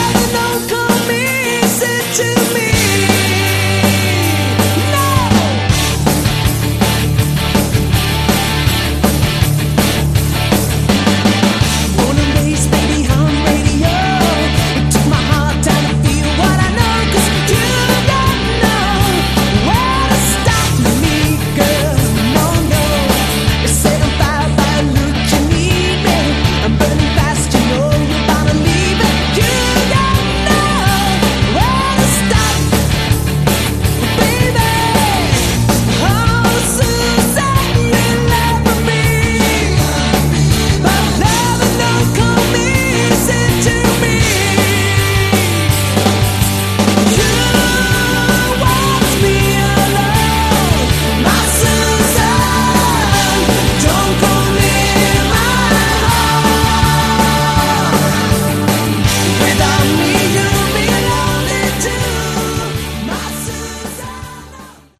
Category: AOR
lead and backing vocals
rhythm guitars, keyboards
bass, fretless bass, backing vocals
drums, percussion